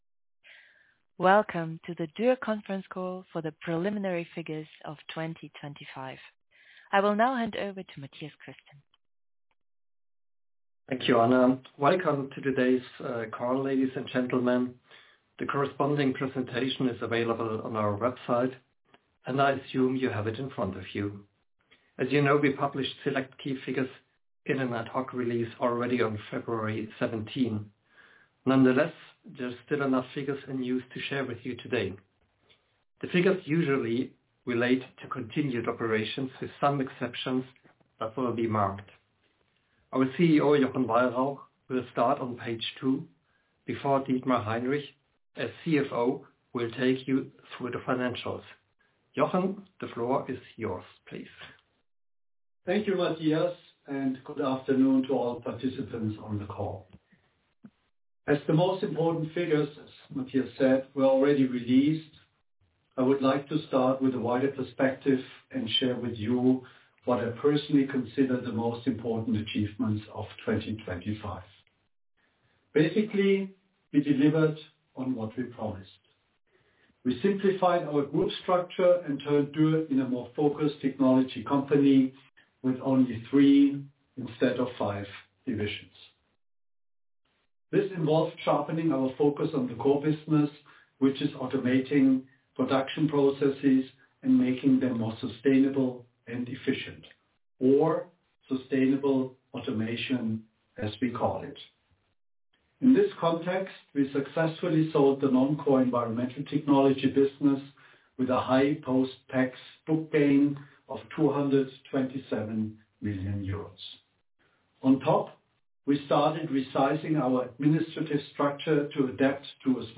Recording Conference Call Q3 2025